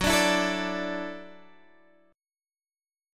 Listen to Gbm7#5 strummed